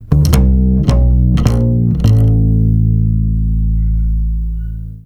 StickBass 7 F.wav